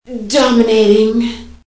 Index of /cstrike/sound/female